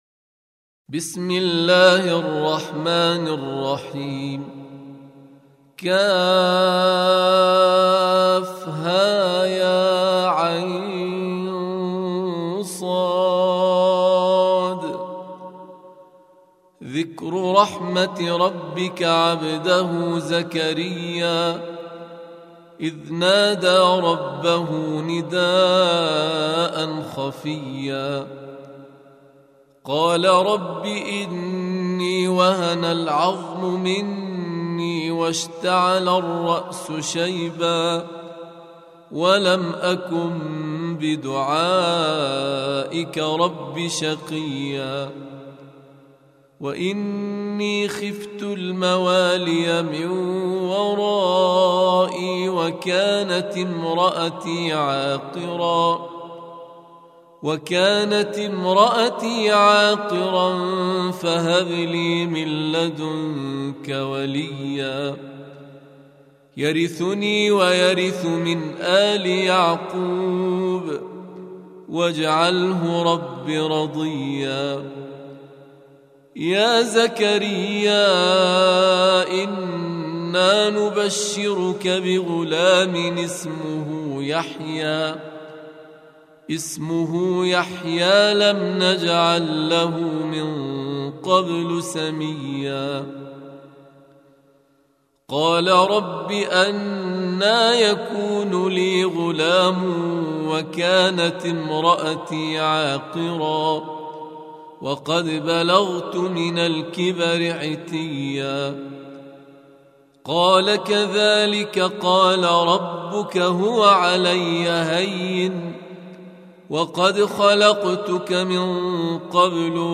19. Surah Maryam سورة مريم Audio Quran Tarteel Recitation
Surah Repeating تكرار السورة Download Surah حمّل السورة Reciting Murattalah Audio for 19.